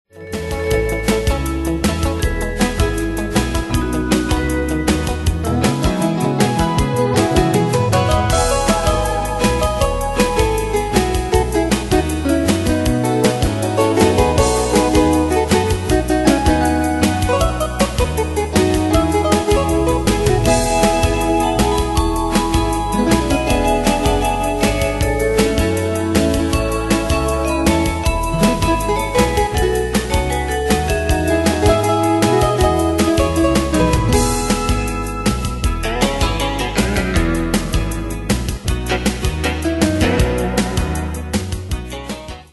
Style: Country Ane/Year: 2001 Tempo: 158 Durée/Time: 3.23
Danse/Dance: LineDance Cat Id.